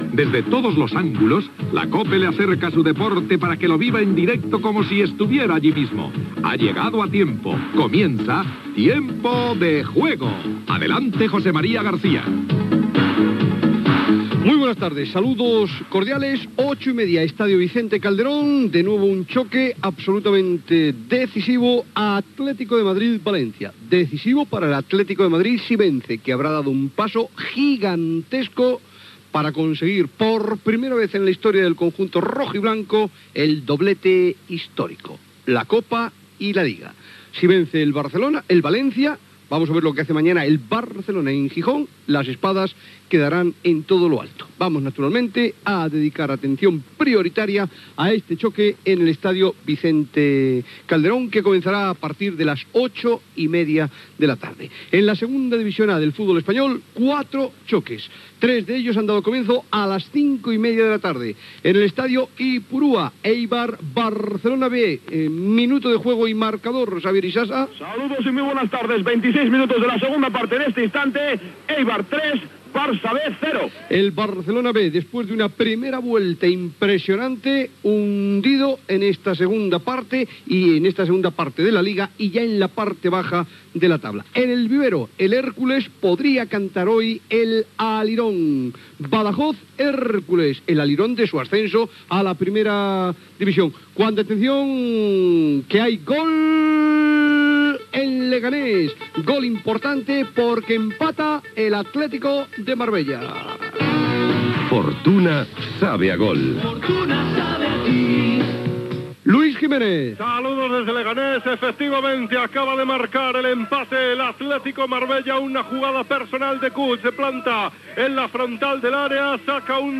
Careta del programa, resum dels principals partits de les lligues de futbol masculina de primera i segon adivisió. Gol del Leganés. Connexió amb els camps del Badajoz i l'Extremadura. Publicitat. Connexió amb el partit de la Recopa d'Europa d'Handbol. Gol de l'Hèrcules d'Alacant al camp del Badajoz
Esportiu